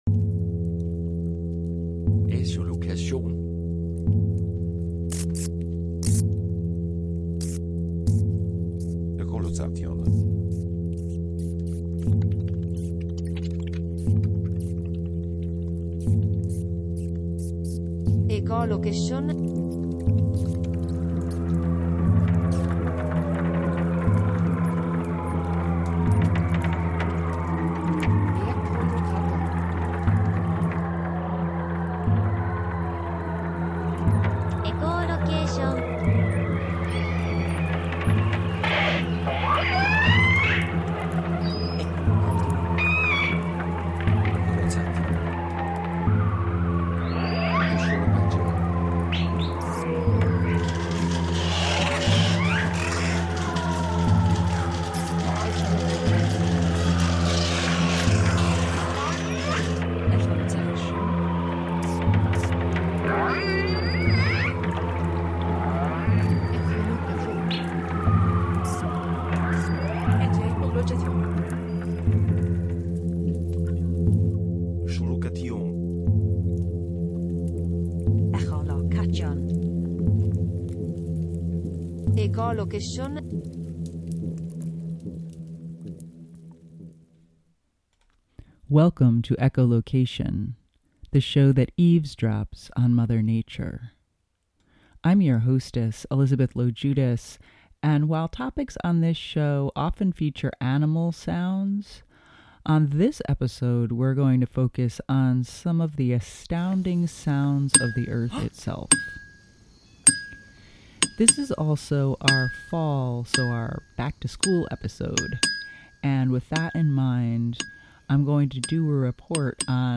Take a sonic field trip to the Ringing Rocks County Park in Upper Black Eddy, PA to hear boulders that sound like bells when struck.